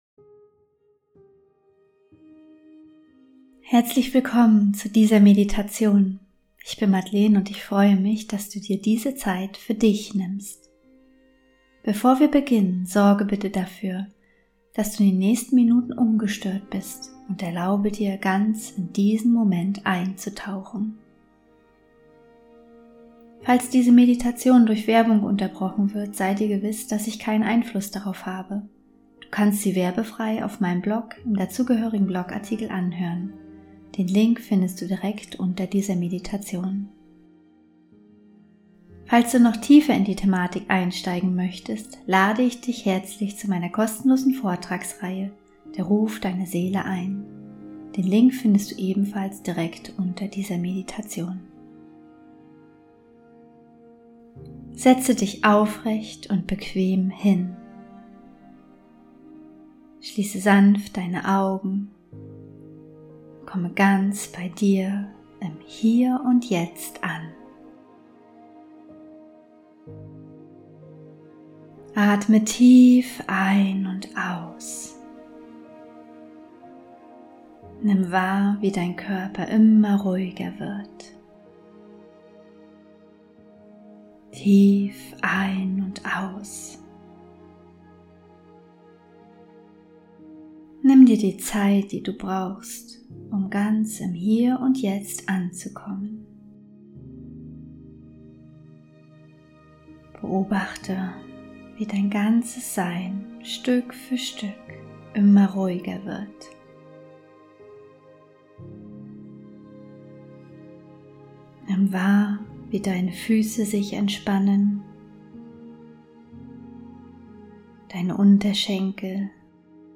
20 Min geführte Meditation: Finde zurück zu deiner inneren Kraft ~ Heimwärts - Meditationen vom Funktionieren zum Leben Podcast
Willkommen zu dieser 20-minütigen geführten Meditation zur Rückverbindung mit deinem ursprünglichen Sein.
Meditation_zurueck_zu_deiner_inneren_Quelle.mp3